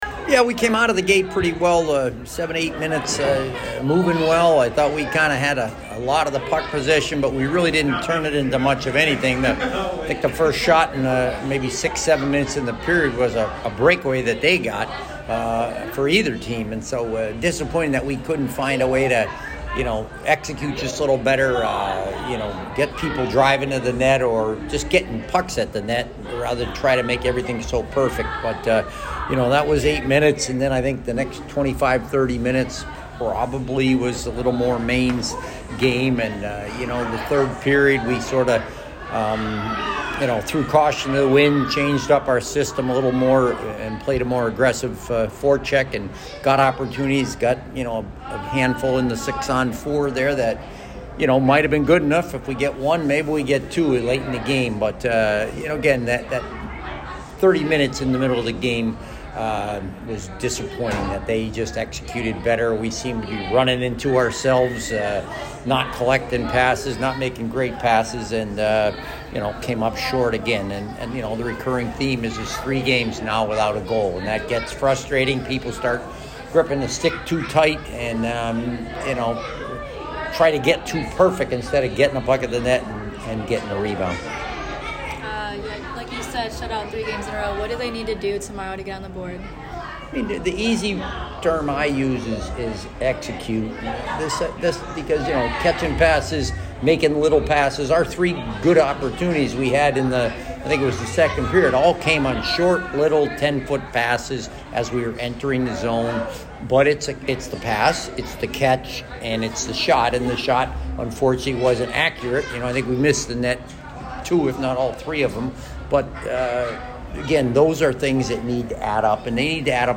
Women’s Ice Hockey / Maine Postgame Interview (12-2-22)